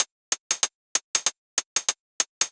Index of /musicradar/ultimate-hihat-samples/95bpm
UHH_ElectroHatC_95-03.wav